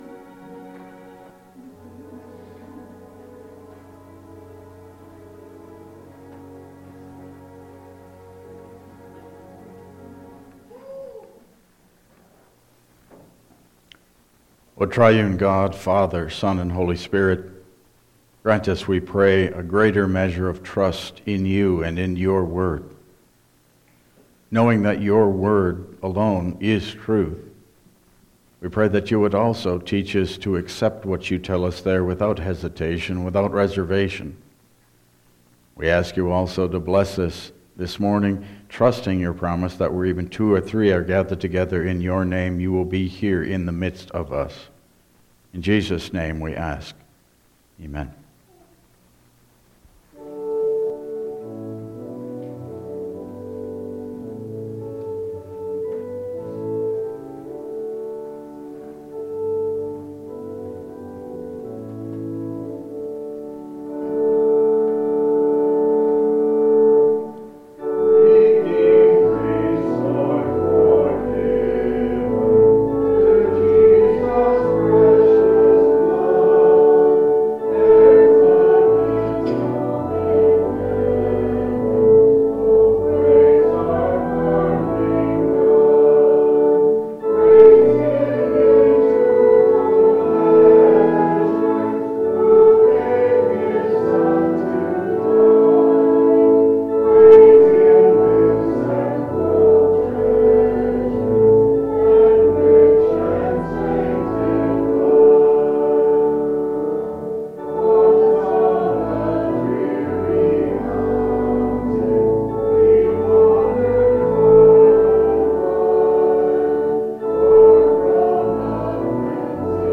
Passage: Acts 11:1-18 Service Type: Regular Service